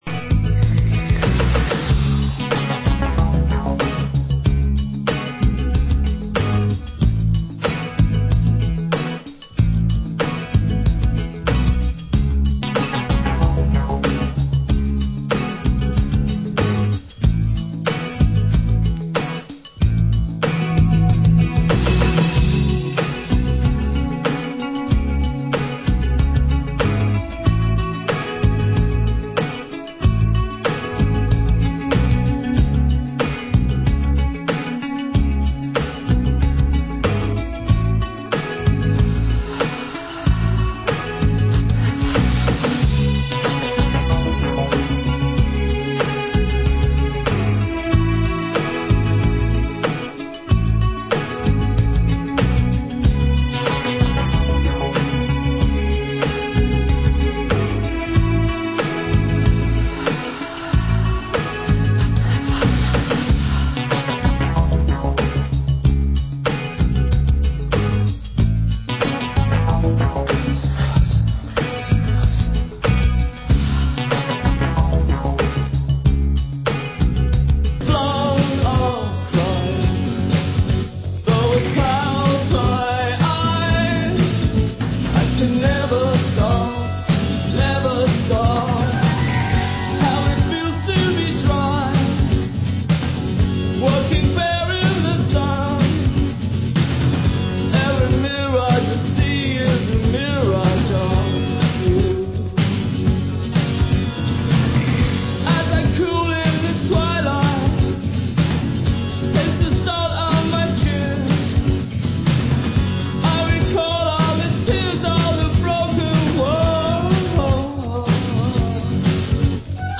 Genre: Balearic